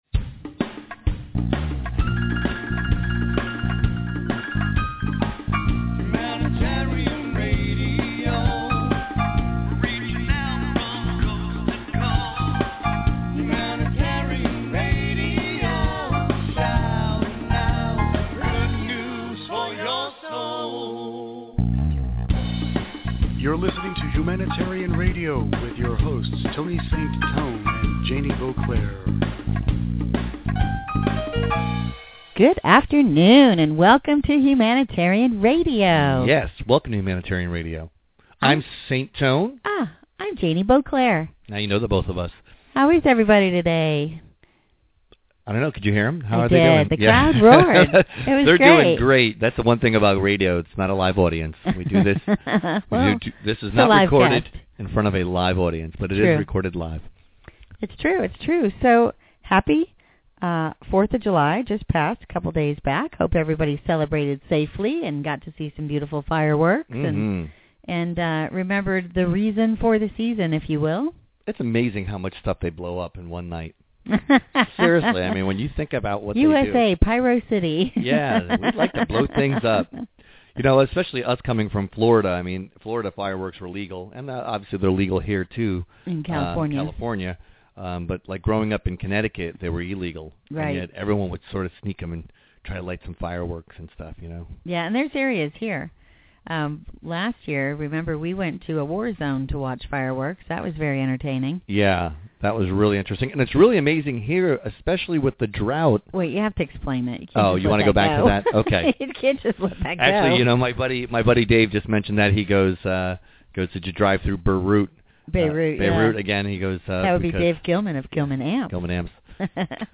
Self Help HR Interview